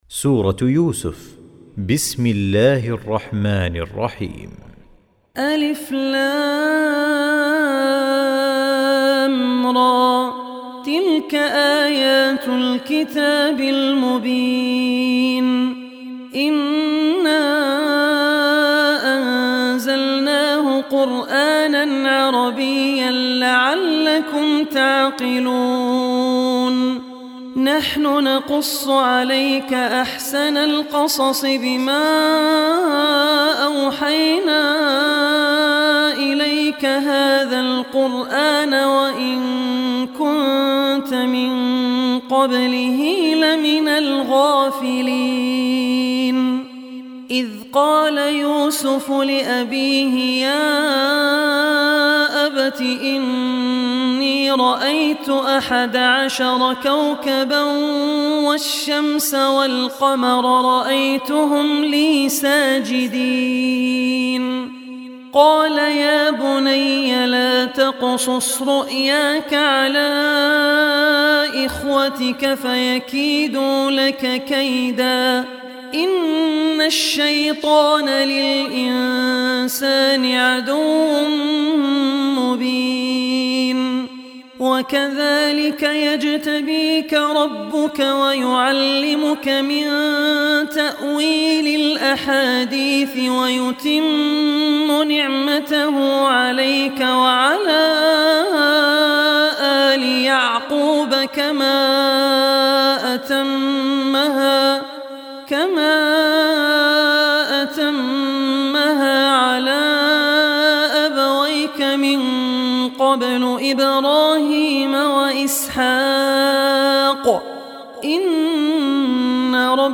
recitation
12-surah-yusuf.mp3